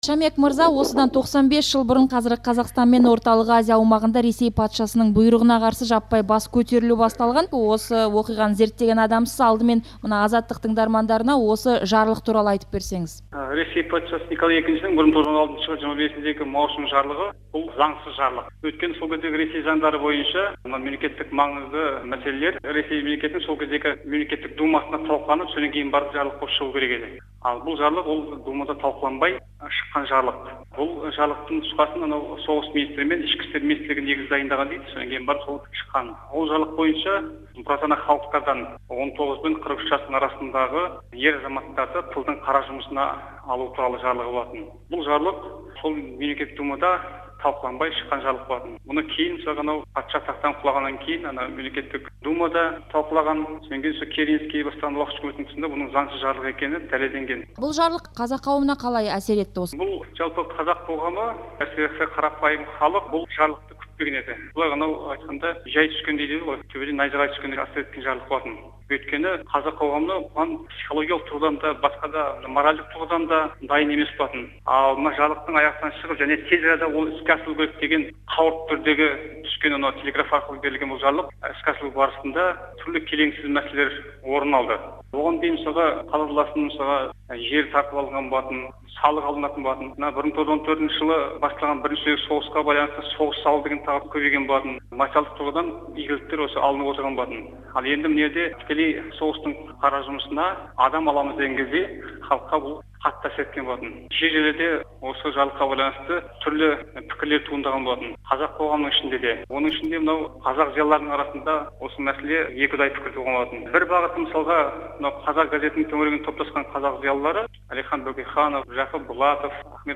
Маусым жарлығына қатысты сұқбатты тыңдаңыз